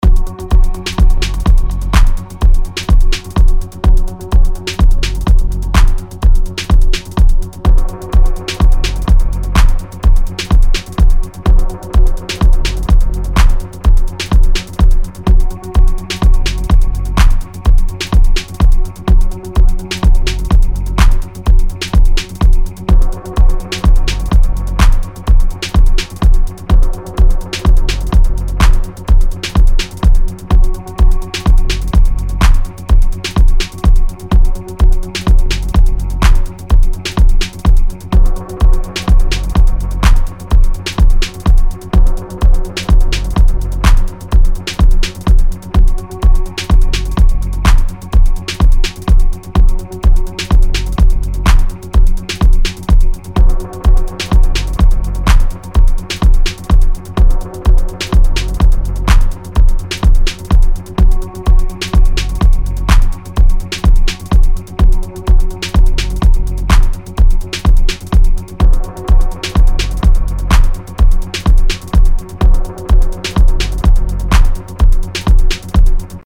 Short deep techno passage - the drums are real.mp3
Short passage made in ableton Live, Jeff Mills like background and thumpy techno kick.